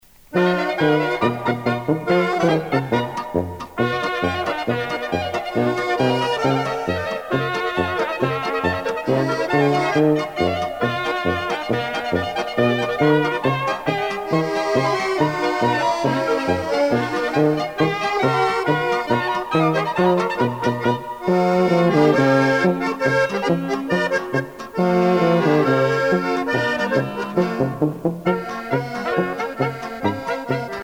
Usage d'après l'analyste gestuel : danse ;